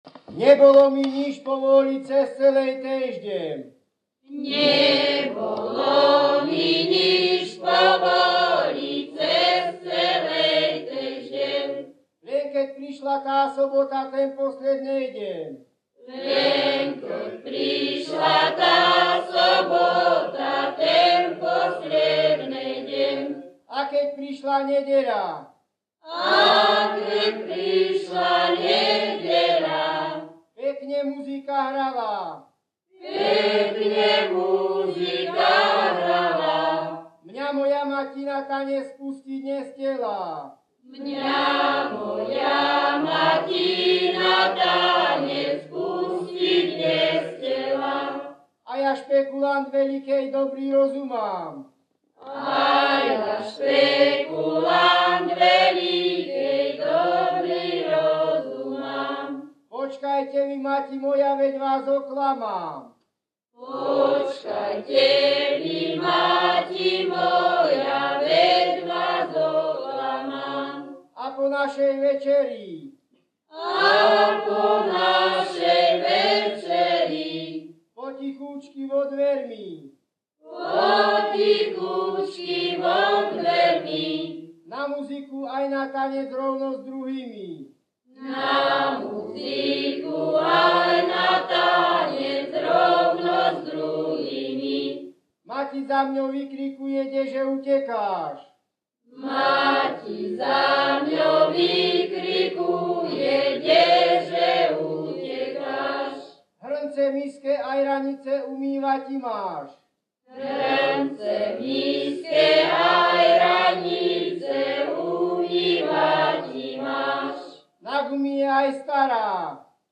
Descripton skupina spevákov bez hudobného sprievodu
Najčastejšie sa spievala v rámci zábavy po priadkach alebo páračkách. Forma interpretácie piesne pripomínala spev litánií – katolíckych modlitieb, v ktorých sa predriekavač a veriaci dlhým radom oslovení a prosieb striedavo obracajú k Bohu a svätým.
Performers skupina spevákov z Litavy
Place of capture Litava
Key words ľudová pieseň